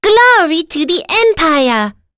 Rodina-Vox_Victory.wav